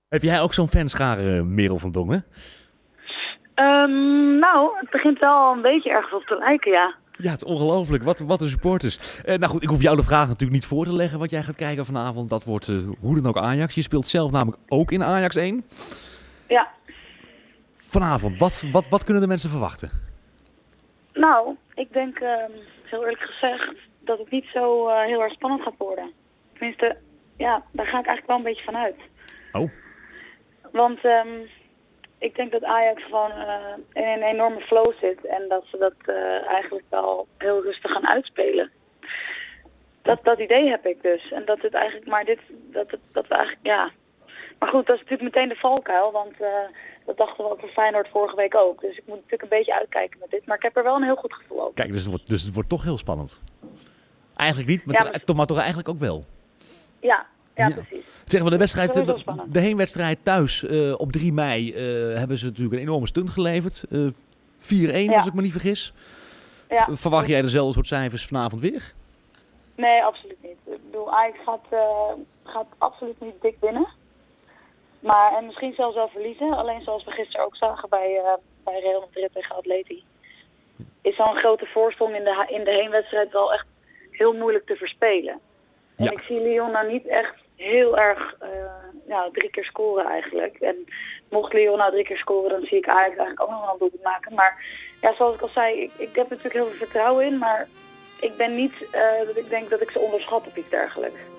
ging vast de straat op om Amsterdam te vragen: waar kijk jij naar vanavond?